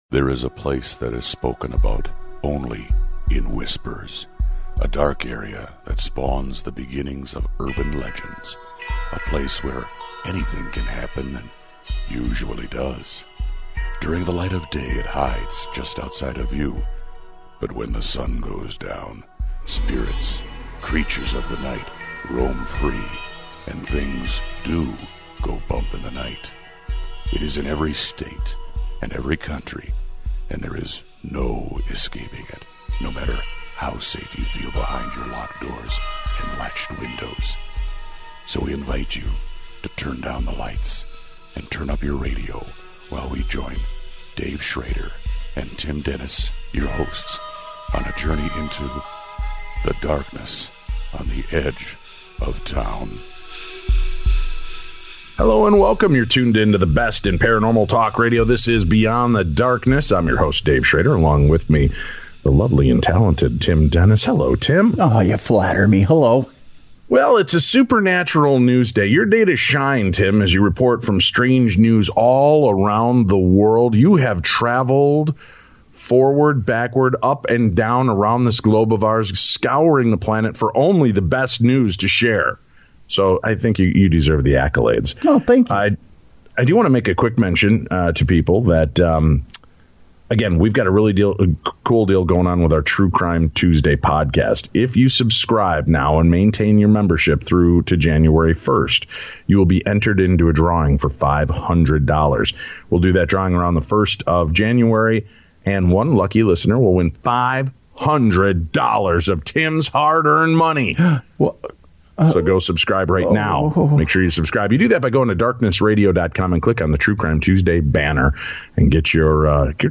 PLUS we read your emails in our ParaShare segment.